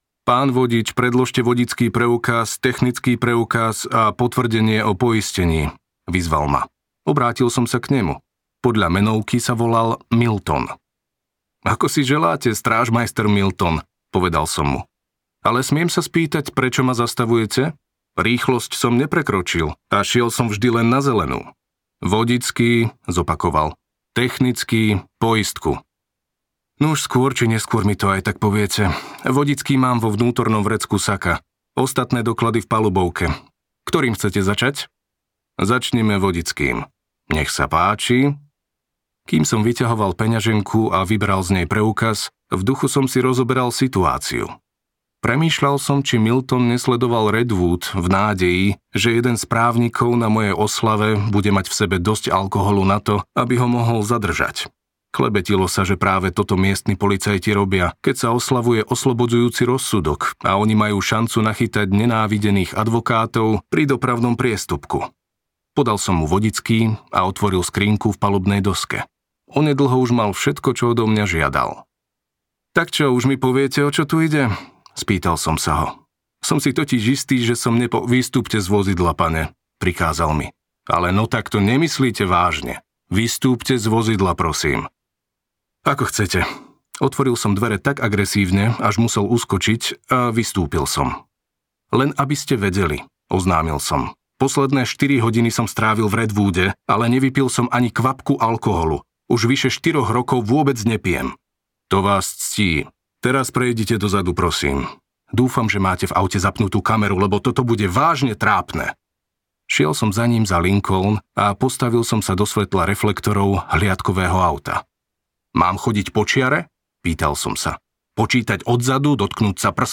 Zákon neviny audiokniha
Ukázka z knihy